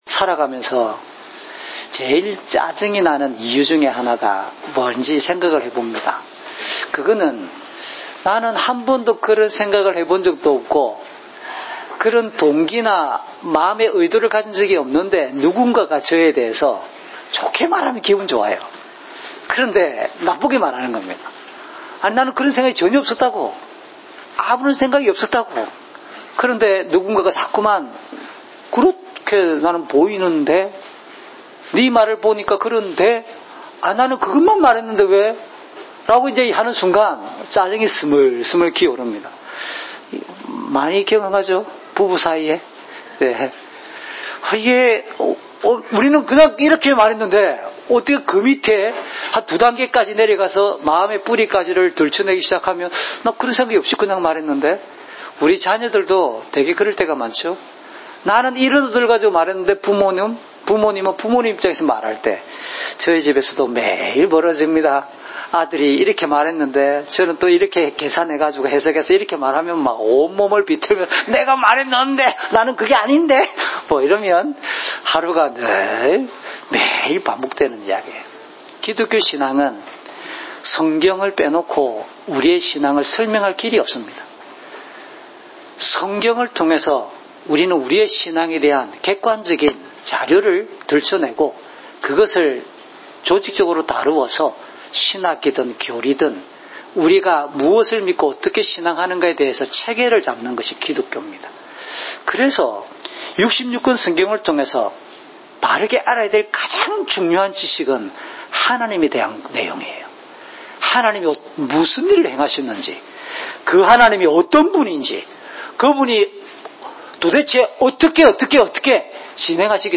주일설교 - 2020년 1월 5일 “하나님의 사랑 안에 거합시다!"(요3:1~21)